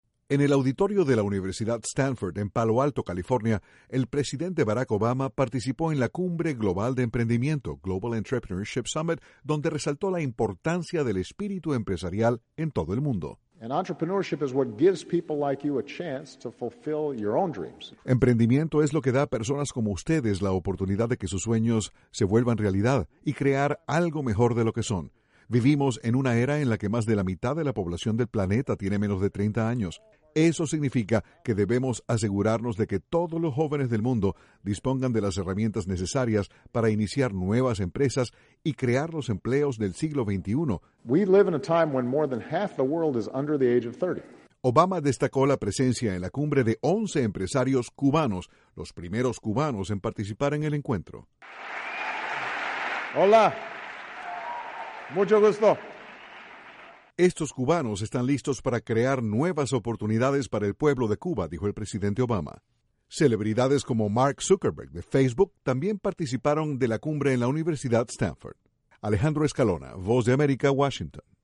El presidente Barack Obama participó en una cumbre empresarial en California y expresó su esperanza de que la juventud cree más empleos en todo el mundo. Desde la Voz de América, Washington, informa